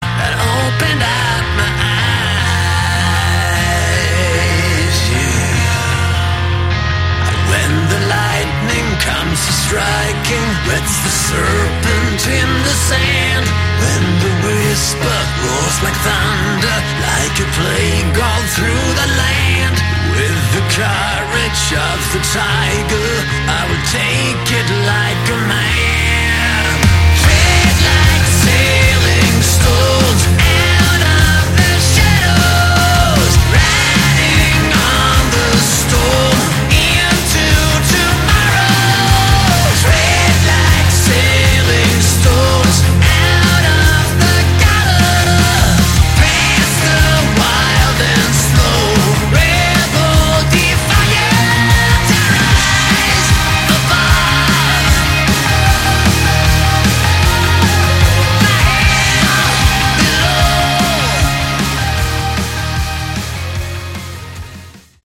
Category: Hard Rock
vocals, guitar, keyboards
guitar, bass, keyboards, backing vocals
drums